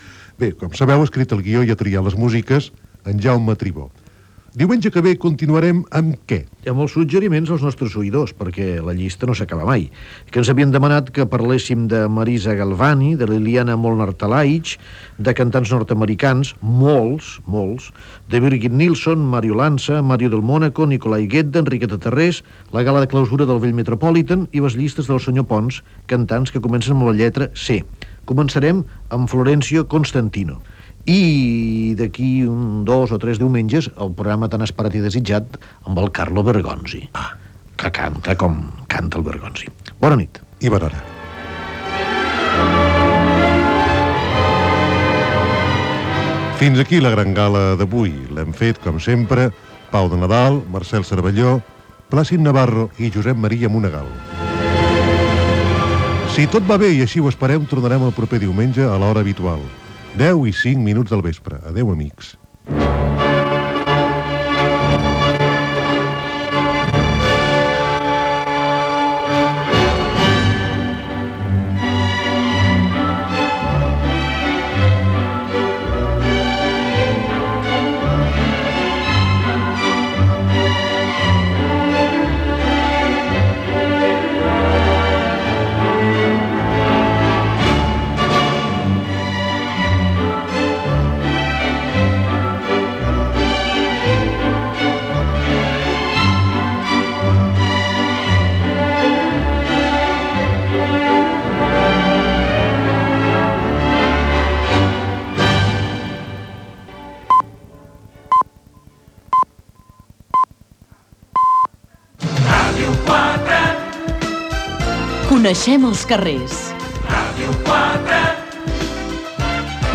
Comiat del programa, amb avanç del proper i equip. Indicatiu de Ràdio 4 "Marca l'accent".
Musical